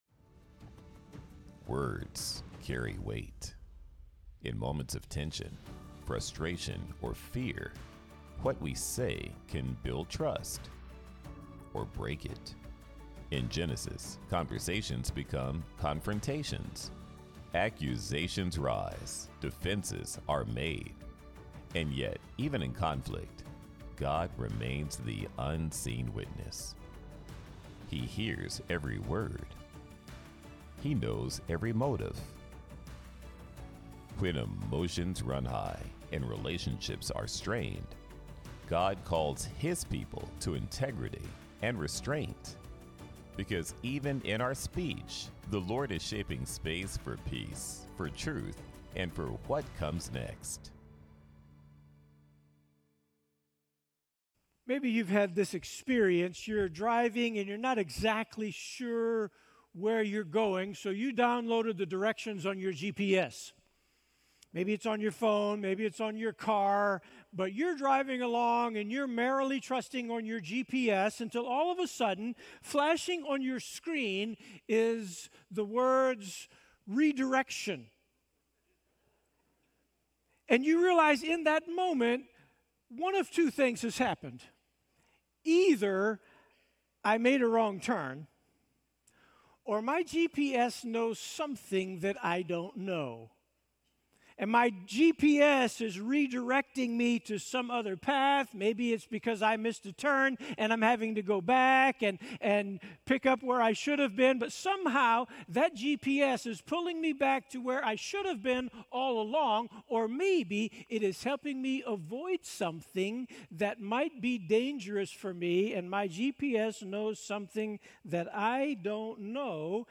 Sermon Listen Worship Jacob's 20-year journey in Paddan Aram teaches us how God redirects our lives through changing circumstances and clear communication.